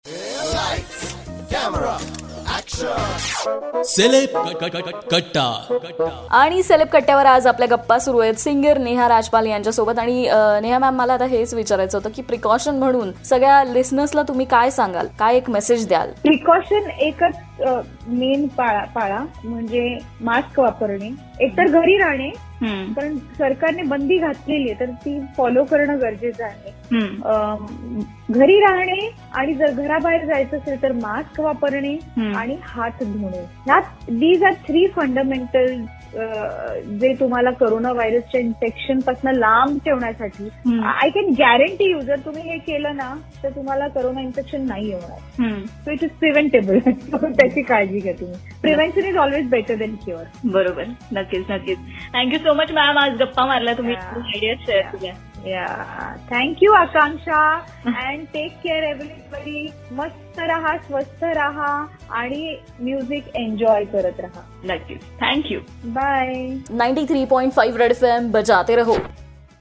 In this interview gave some precautionary tips for listeners..